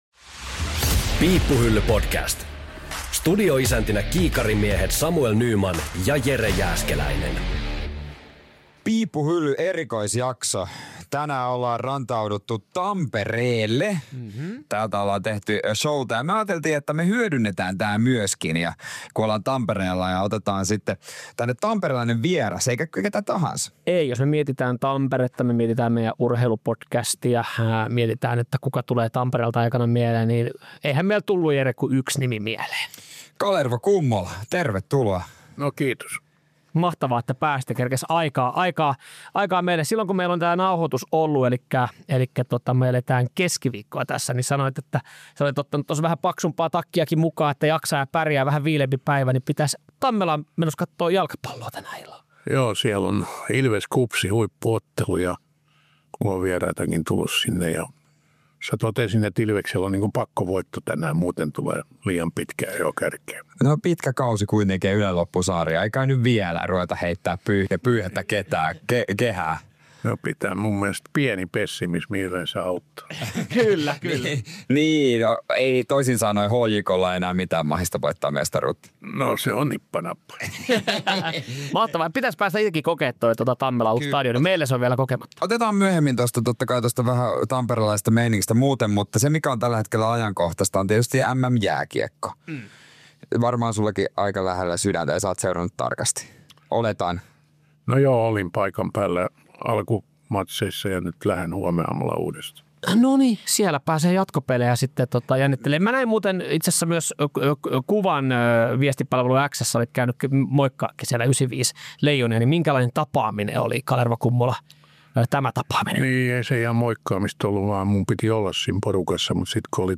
Tässä jaksossa käydään läpi veikkaukset Mestareiden liigan otteluparien jatkoonmenijöistä ja fanikulttuuri on myös studiokaksikon tarkastelun alla.